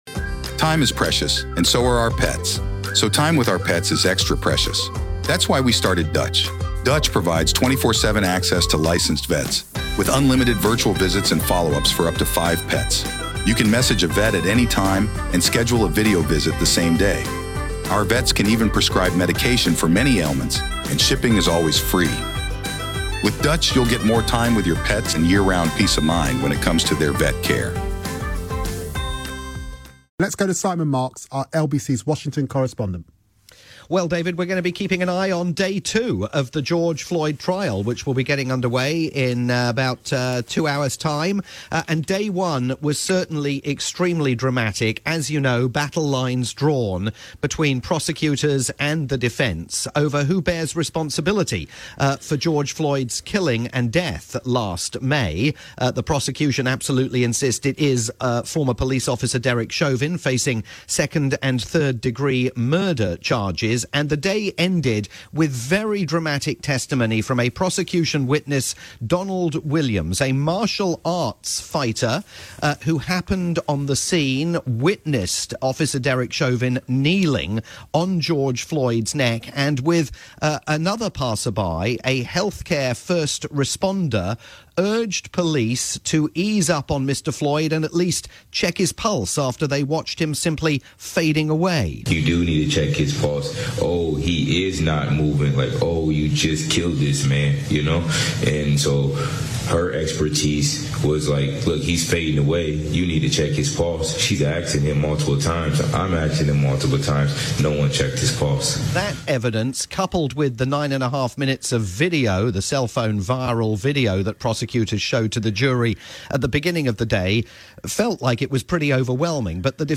live report for David Lammy's programme on the UK's LBC.